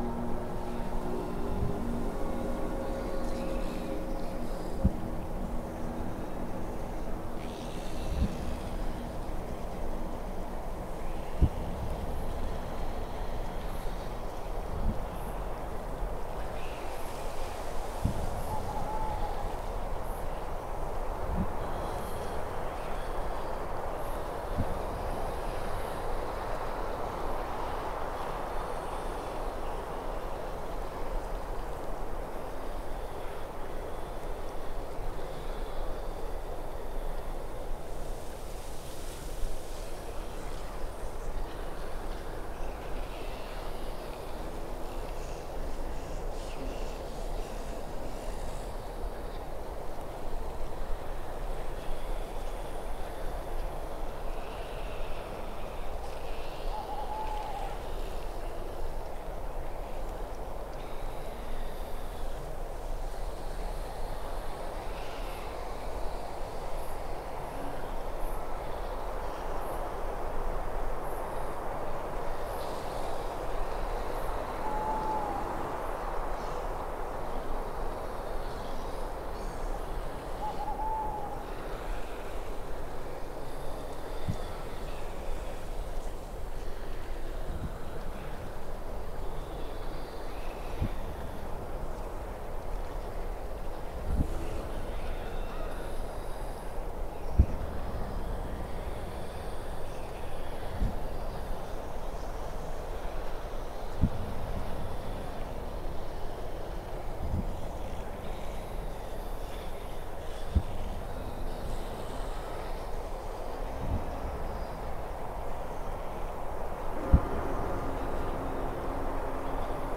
spooky.ogg